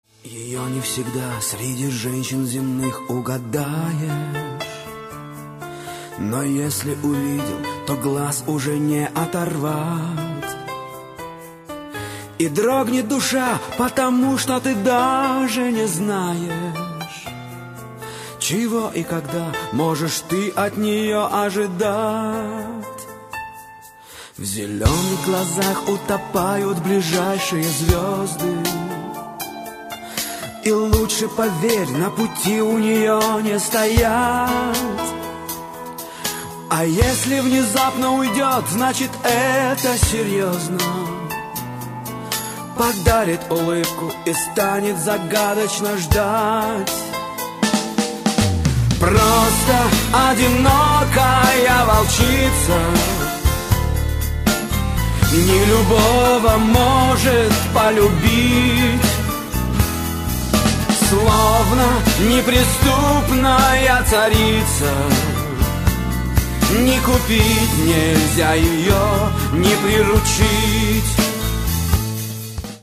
мужской вокал
спокойные
клавишные
русский шансон